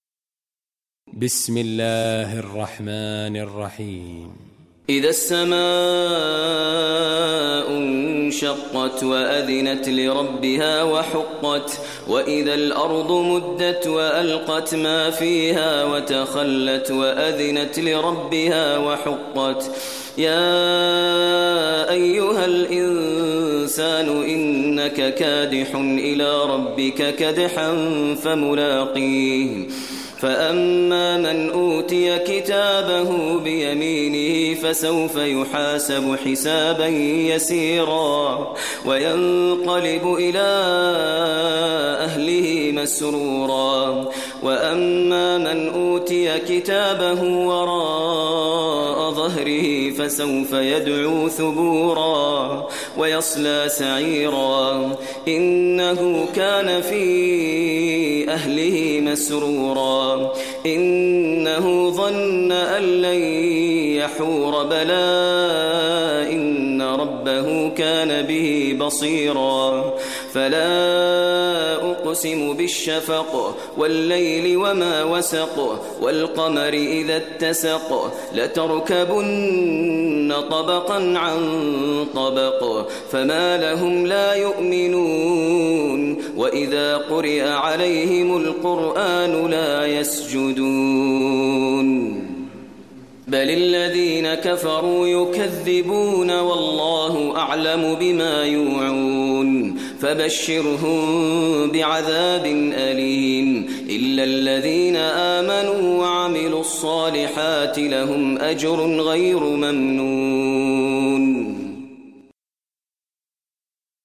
Surah Inshiqaq Recitation by Maher al Mueaqly
Surah Inshiqaq, listen online mp3 tilawat / recitation in Arabic recited by Imam e Kaaba Sheikh Maher al Mueaqly.